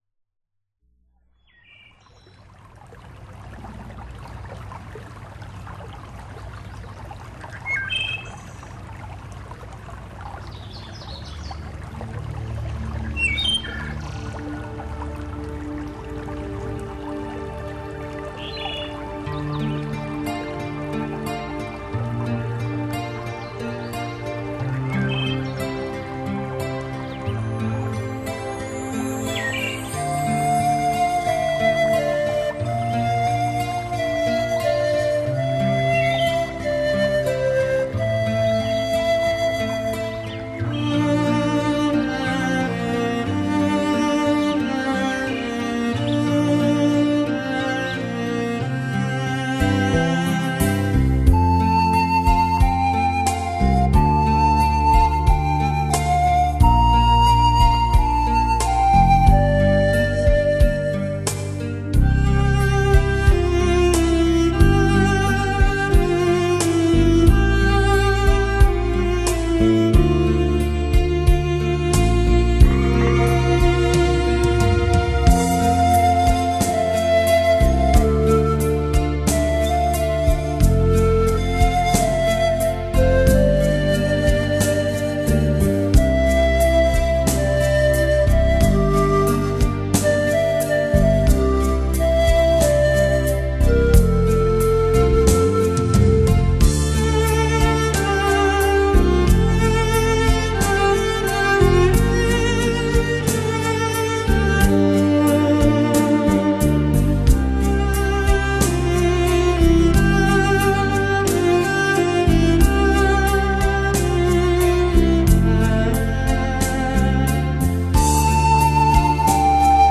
相信您听后一定会减压、极度的松弛！
（试听曲为低品质wma，下载为320k/mp3）